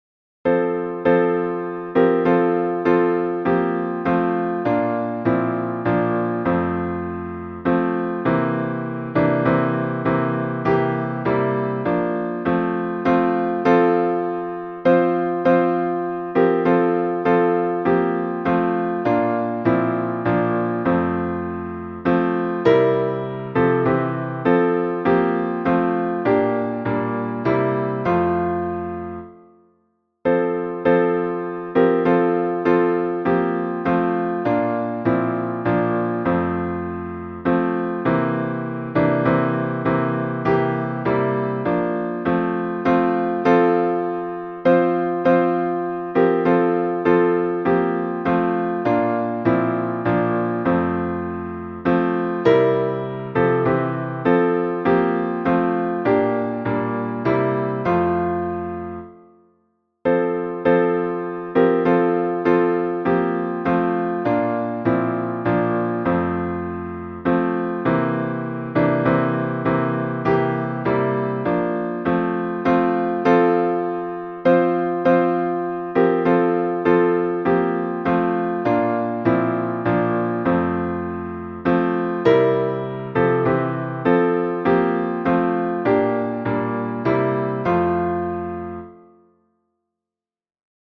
An extremely popular Christmas hymn, first published in the late 19th century and often falsely attributed to Martin Luther.